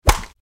Bullet Hits Body With Wet Impact, X5